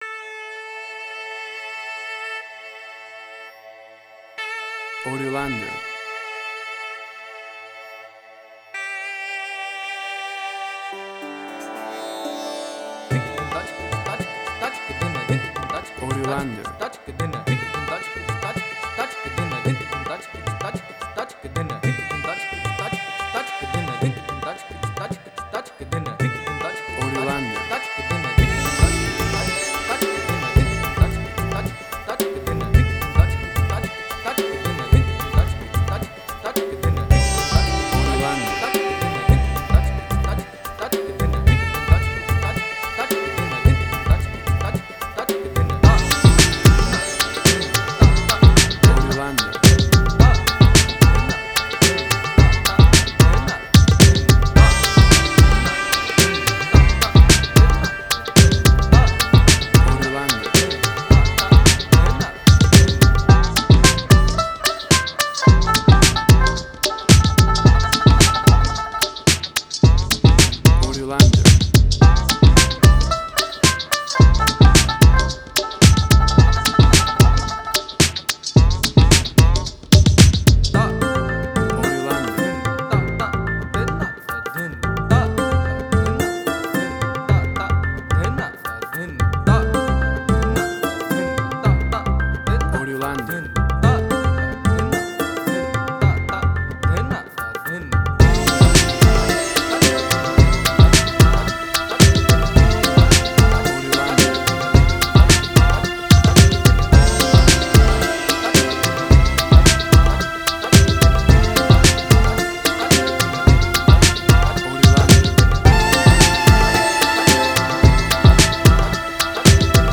Indian Fusion
Tempo (BPM): 110